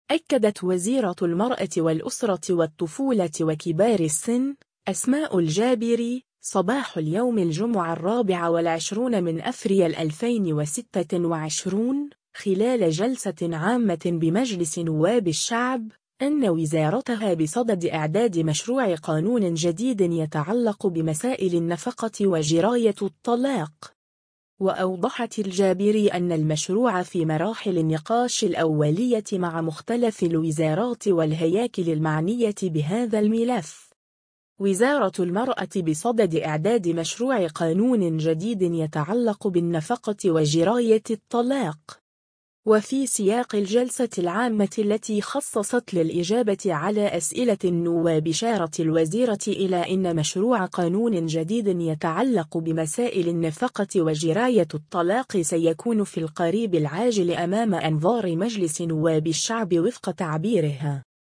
أكدت وزيرة المرأة والأسرة والطفولة وكبار السن، أسماء الجابري، صباح اليوم الجمعة 24 أفريل 2026، خلال جلسة عامة بمجلس نواب الشعب، أن وزارتها بصدد إعداد مشروع قانون جديد يتعلق بمسائل النفقة وجراية الطلاق.